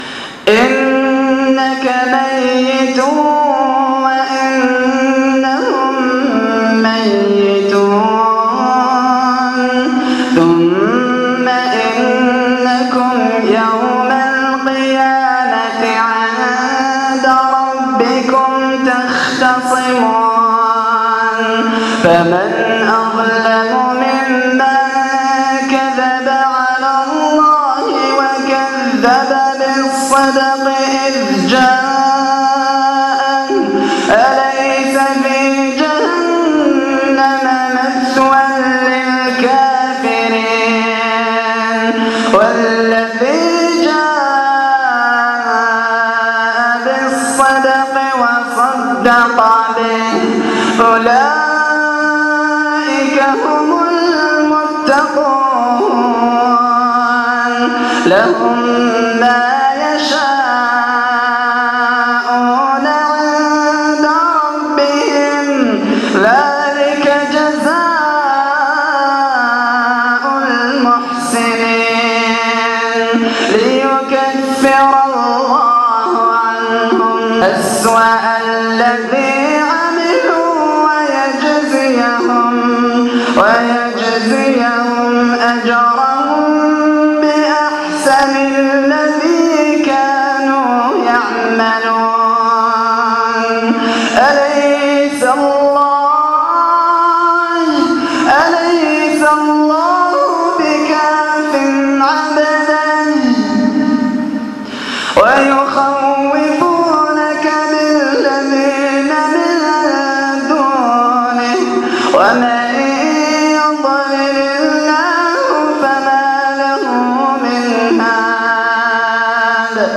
تلاوة غير عادية من صلاة التهجد